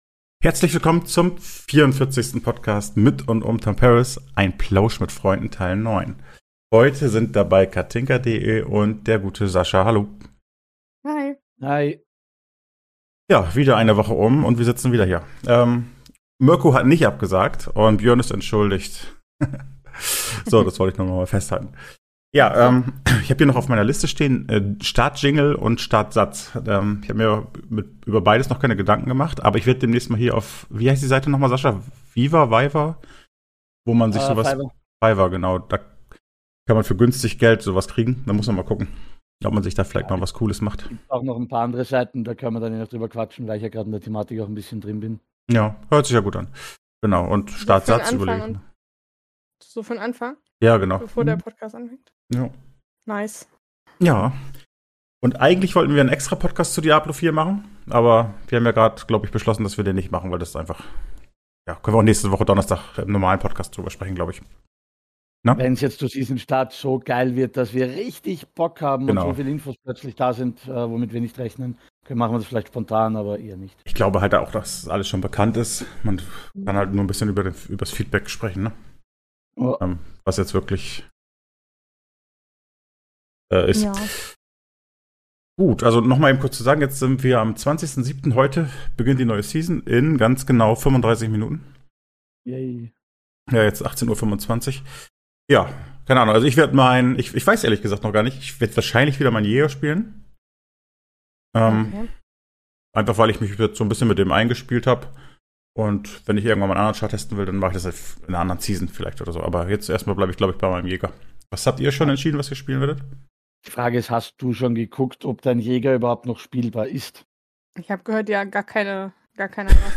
Ein Plausch mit Freunden (9)